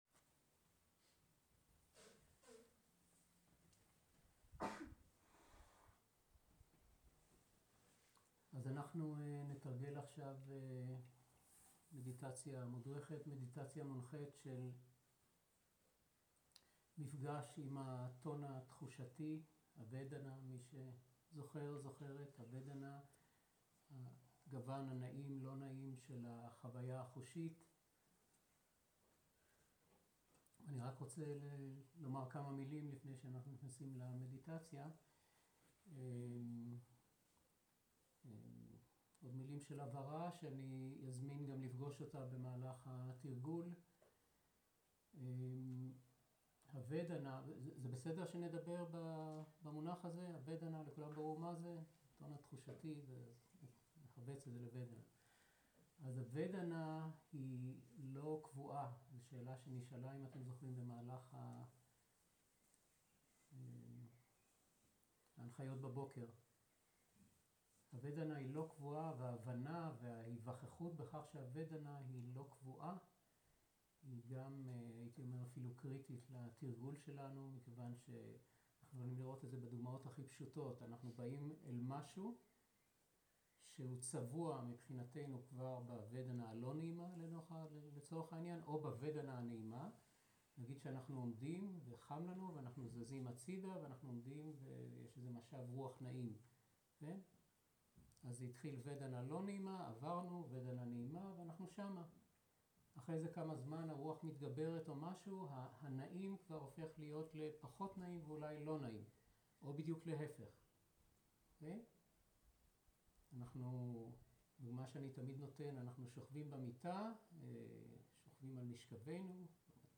Dharma type: Guided meditation